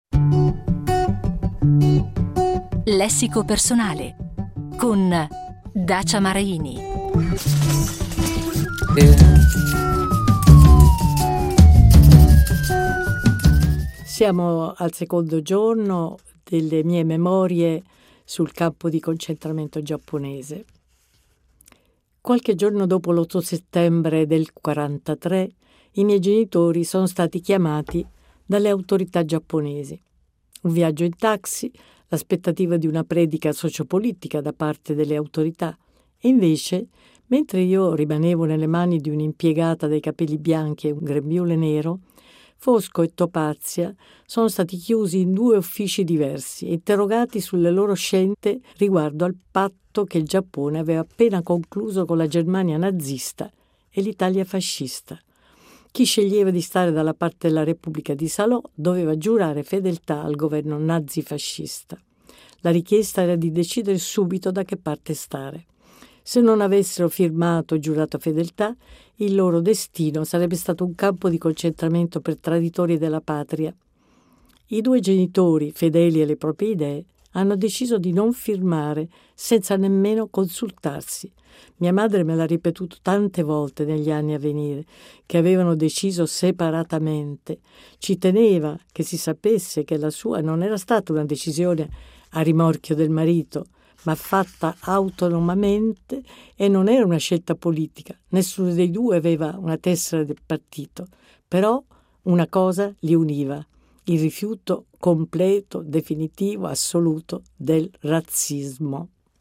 Ogni giorno la scrittrice in piccoli schizzi narrativi ripresi dal libro, ci accompagnerà dentro le memorie di lei bambina prigioniera.